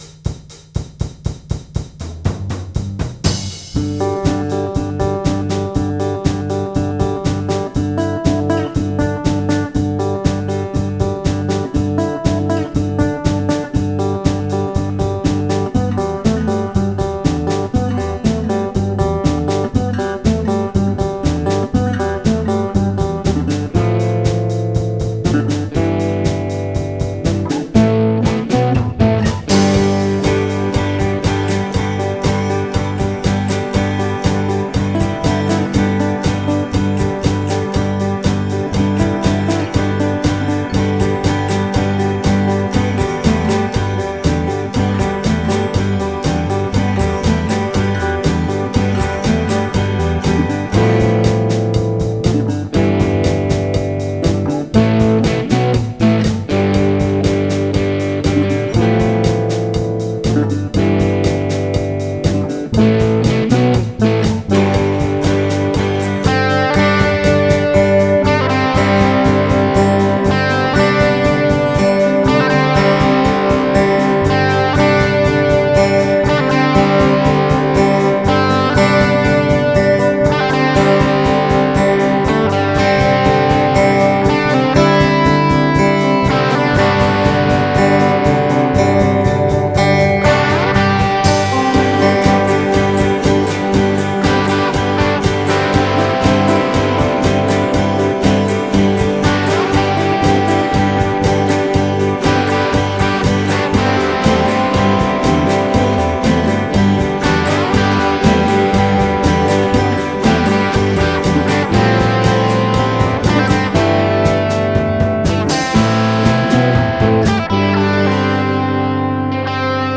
Friday is Guitar Day
Free amateur music!
The usual disclaimer: 1. I admit these are not great music; 2. I claim copyright anyway, so there; 3. No, I cannot do anything about the general quality of the mix, as I am incompetent.
gee-tar